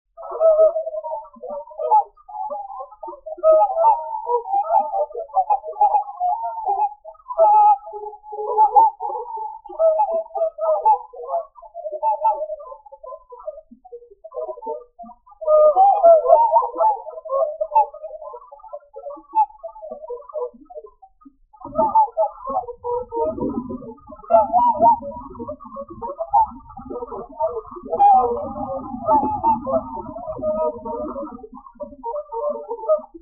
Station 4: Gesang Geräusch: Gesang von Singschwänen. Ruf aus der Ferne � eisiger Winterwind Hoffnung auf Frühling, Zukunft, Frieden.